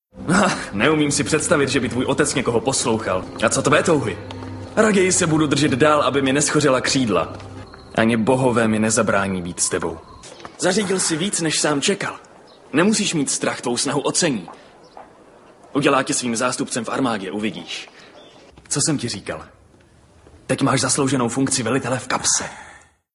VOICE OVER / KOMENTÁŘ / DABING / ZPĚV
5. DABING-Spartacus-seriál.mp3